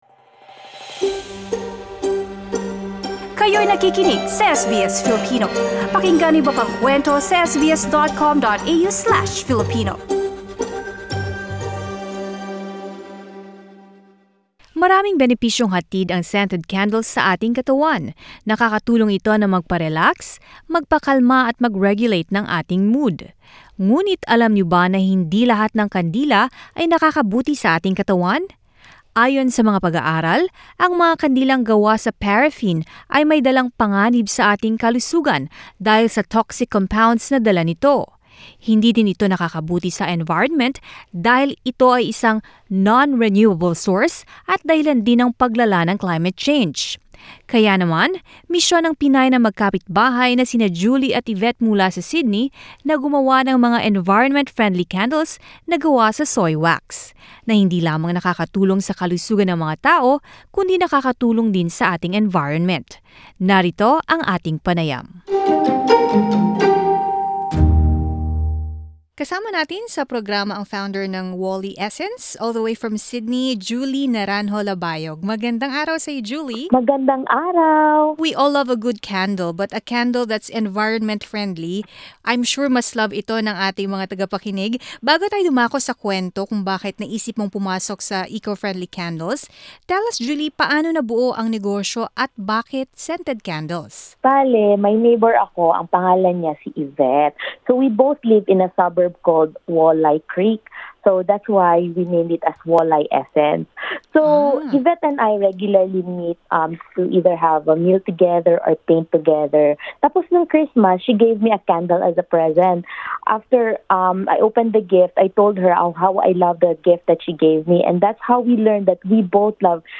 Neighbour turned business partner In an interview with SBS Filipino